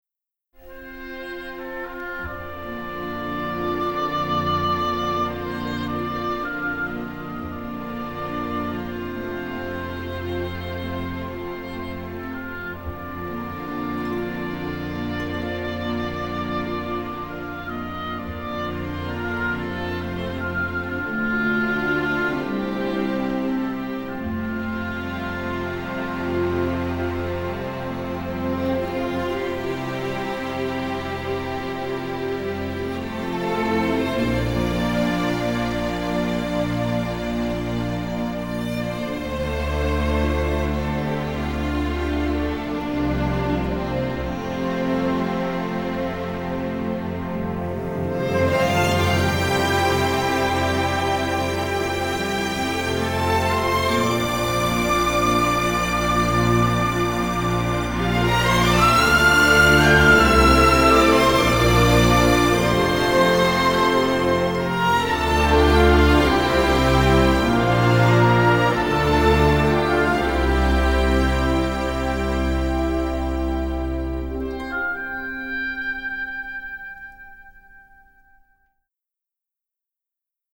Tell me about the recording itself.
Everything has been remastered from superior master elements